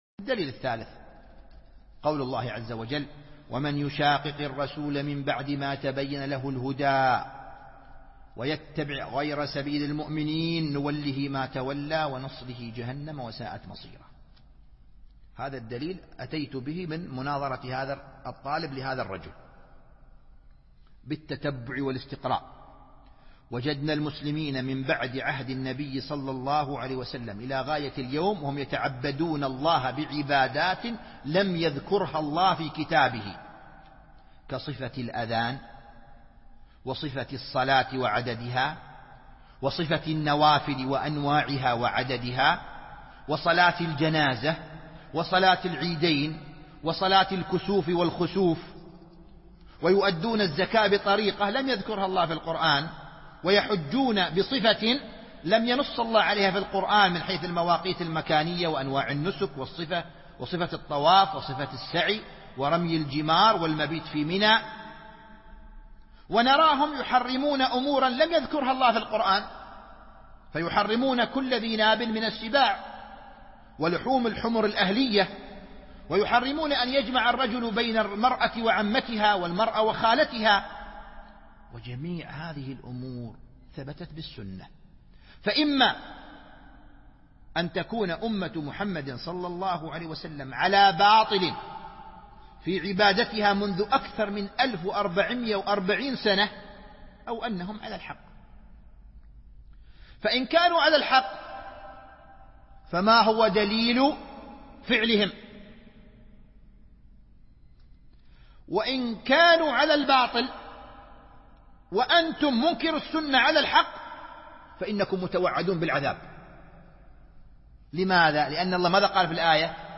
وَنُصْلِهِ جَهَنَّمَ وَسَاءَتْ مصيرا(( الألبوم: شبكة بينونة للعلوم الشرعية المدة: 2:31 دقائق (629.89 ك.بايت) التنسيق: MP3 Stereo 22kHz 32Kbps (VBR)